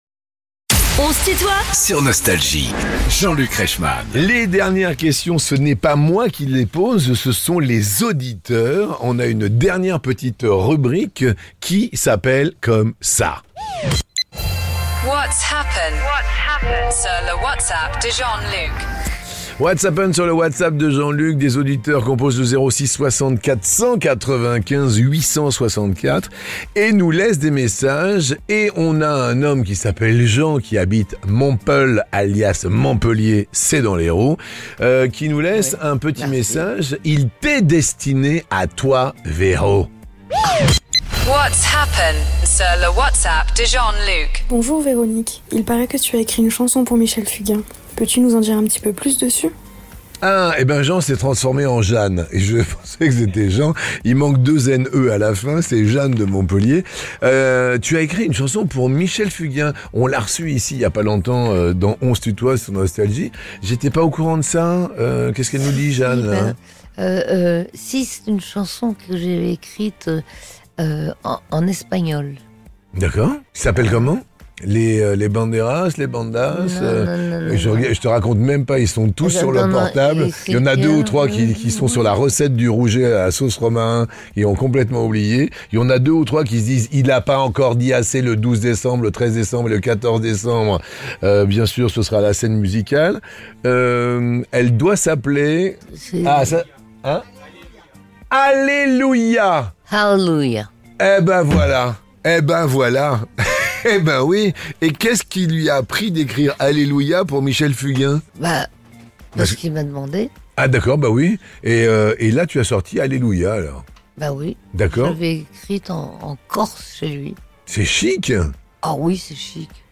Les interviews